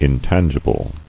[in·tan·gi·ble]